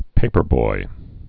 (pāpər-boi)